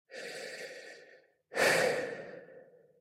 lowoxygen.ogg